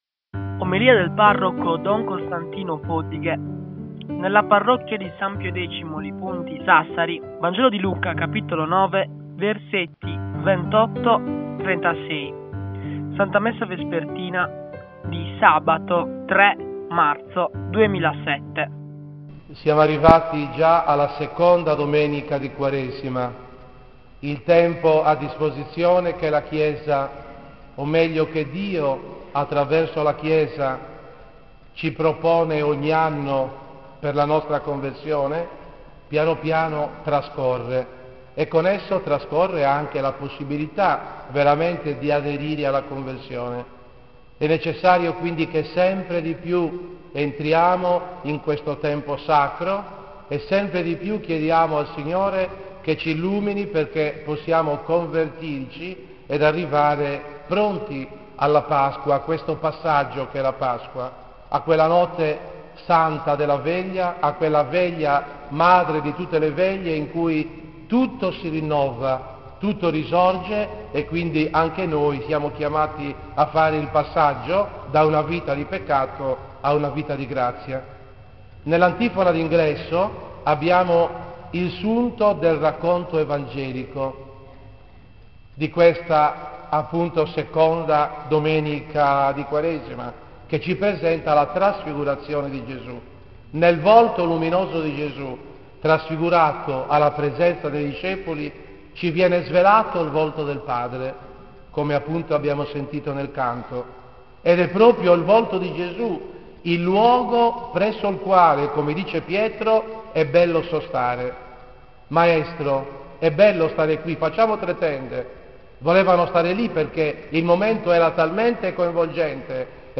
In questa sezione puoi ascoltare le omelie del parroco sul Vangelo della domenica indicata.
OMELIE DELLA QUARESIMA 2007